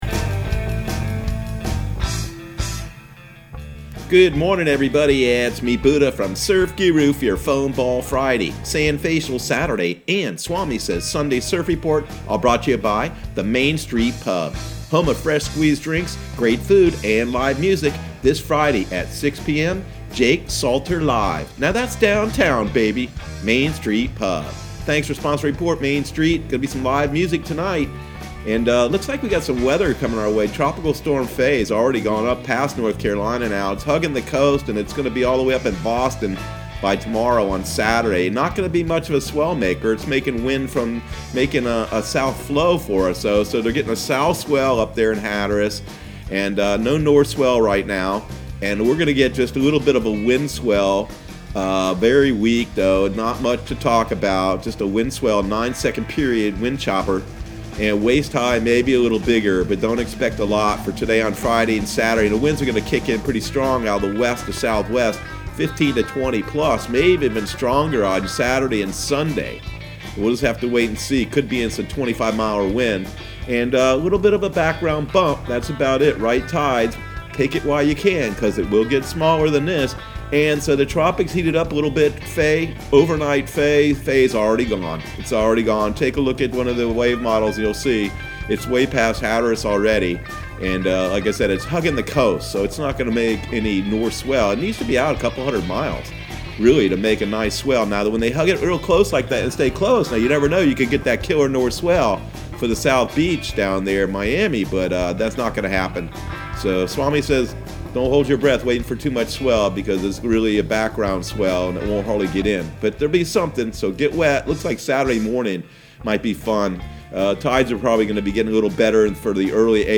Surf Guru Surf Report and Forecast 07/10/2020 Audio surf report and surf forecast on July 10 for Central Florida and the Southeast.